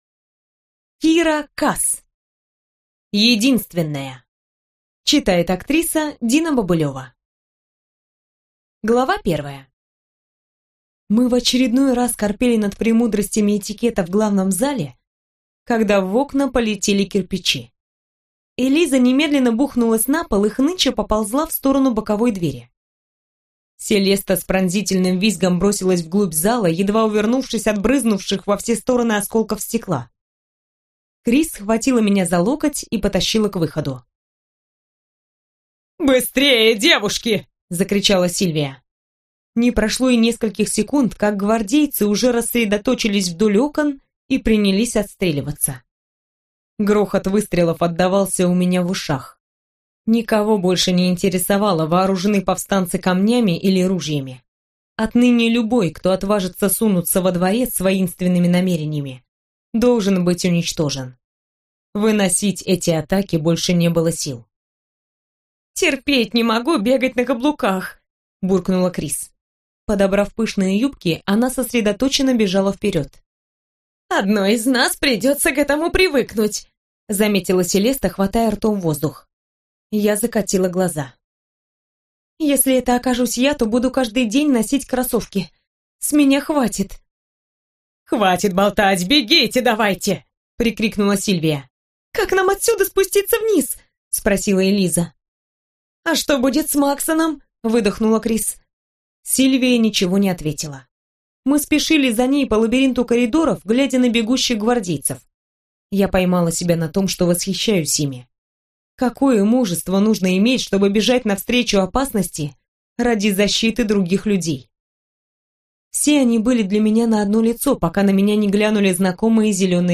Аудиокнига Единственная - купить, скачать и слушать онлайн | КнигоПоиск